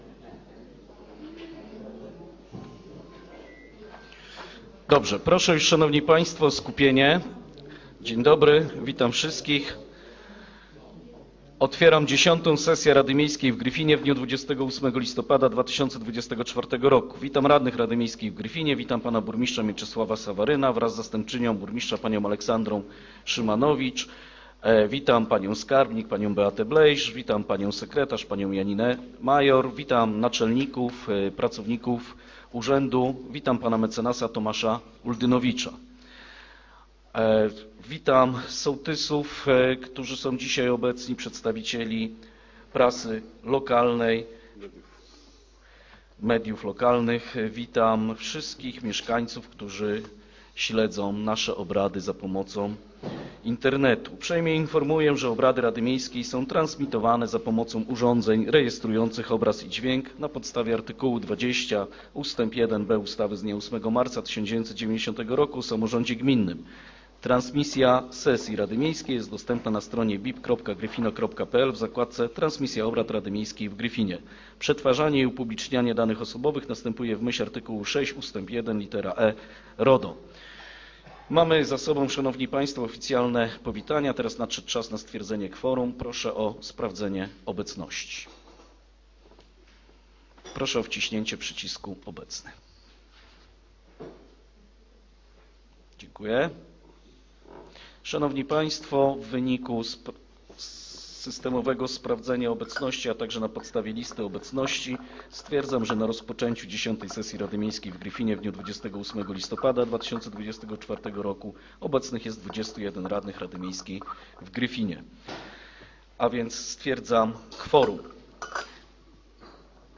Zapis audio przebiegu sesji Biuletyn Informacji Publicznej.
Zapis audio przebiegu 10 SESJI RADY MIEJSKIEJ